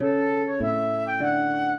flute-harp
minuet1-8.wav